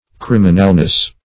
Criminalness \Crim"i*nal*ness\, n.